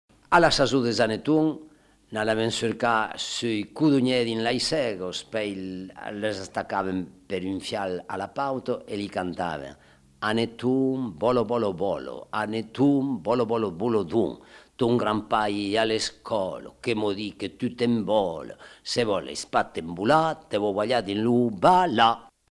Aire culturelle : Haut-Agenais
Genre : forme brève
Type de voix : voix d'homme
Production du son : récité
Classification : formulette enfantine